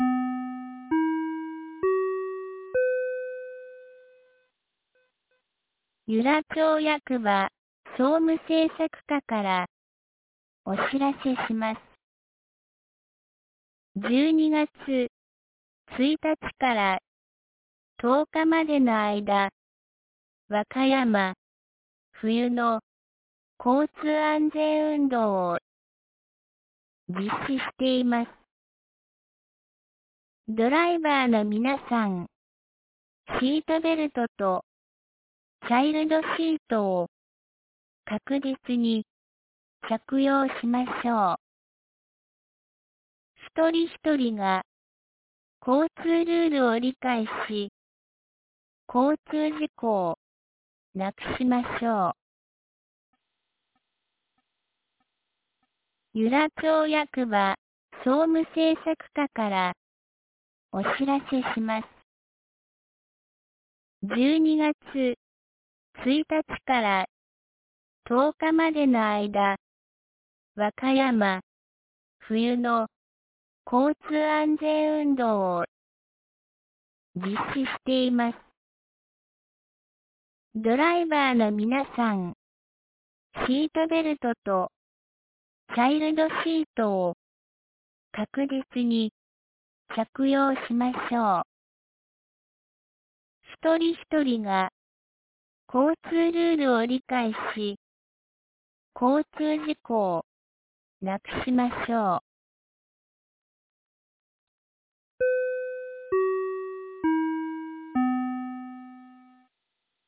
2025年12月04日 17時12分に、由良町から全地区へ放送がありました。